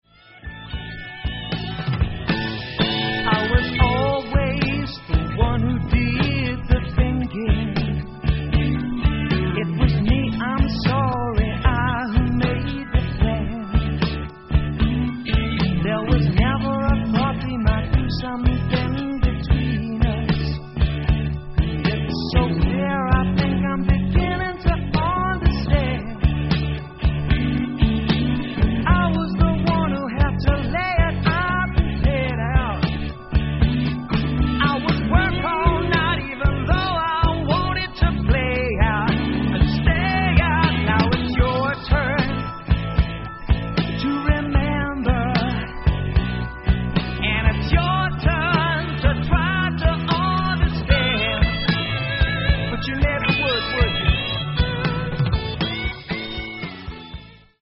Recorded at Lansdowne and Morgan Studios,